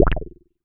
Wet_Bass_E1.wav